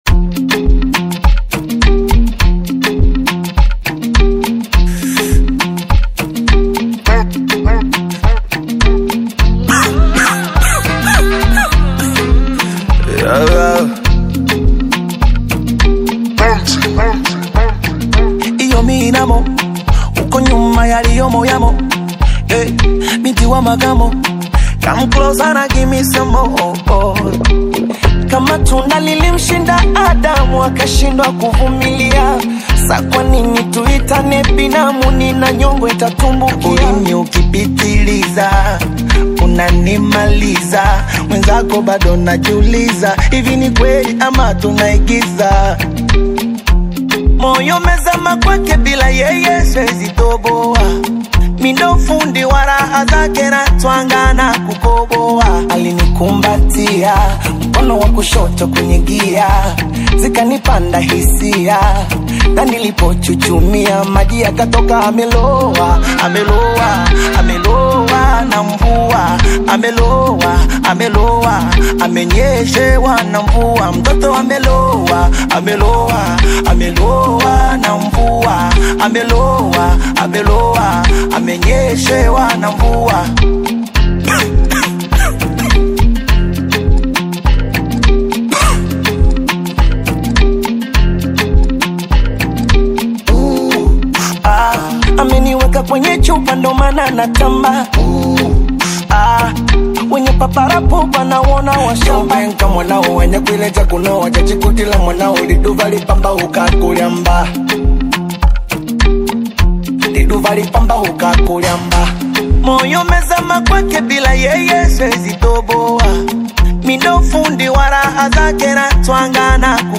Popular Tanzanian urban music